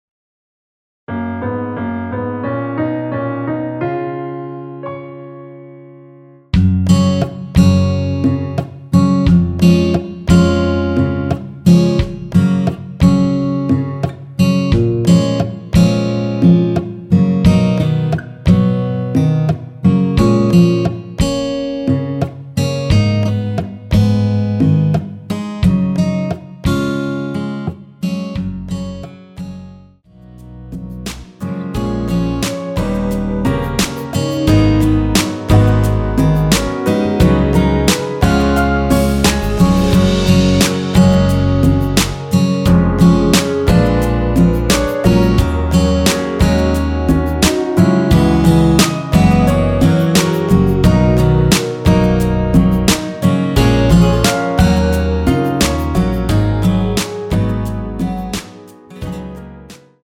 원키에서(-3)내린 MR입니다.
F#
앞부분30초, 뒷부분30초씩 편집해서 올려 드리고 있습니다.
중간에 음이 끈어지고 다시 나오는 이유는